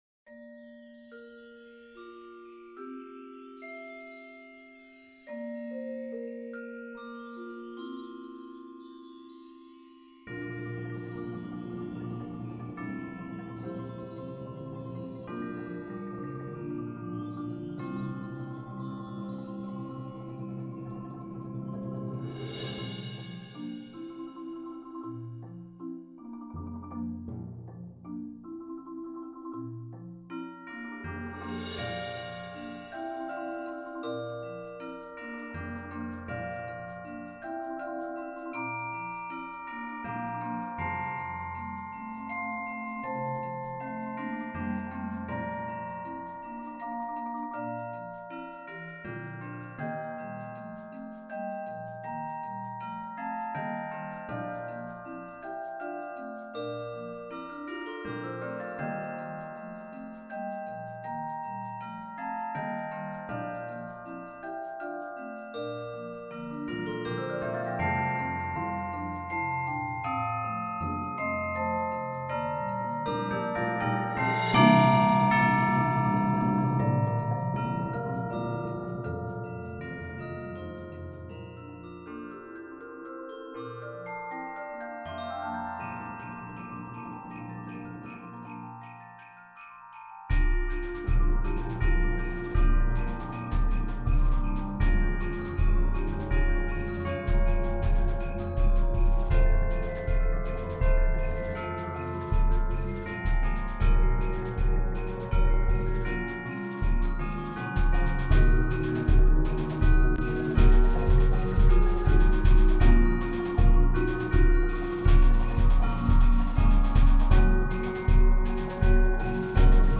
Genre: Percussion Ensemble
Bells
Vibraphone
Wind Chimes, Triangle, Field Drum (or deep snare drum)
Concert Bass Drum, Egg Shaker
Suspended Cymbal, Sleigh Bells